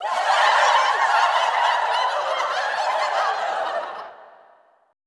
LAUGHTER 3-R.wav